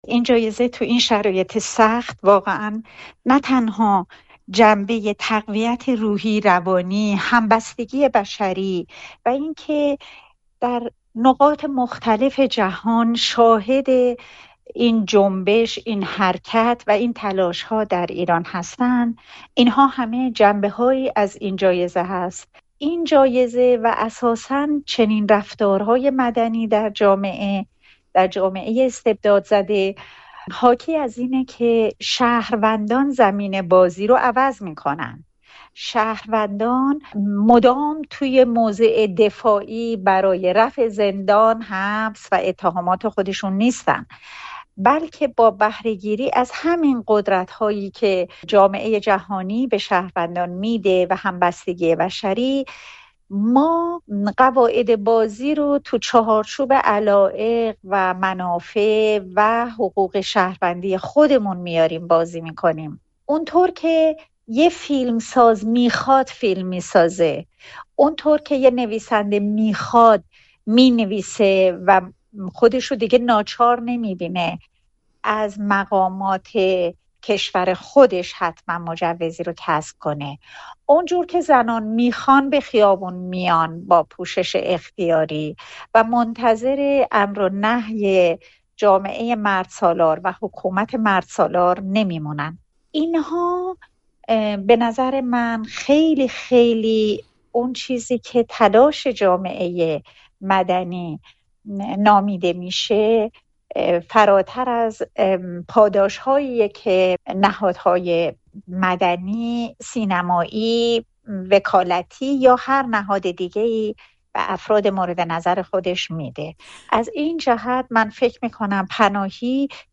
تاثیر جایزهٔ جعفر پناهی بر جامعه مدنی ایران در گفت‌وگو با نسرین ستوده